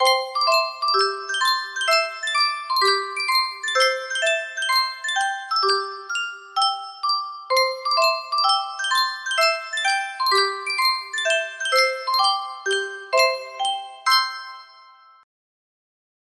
Sankyo Music Box - Antonin Dvorak Humoresque FP music box melody
Full range 60